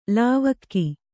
♪ lāvakki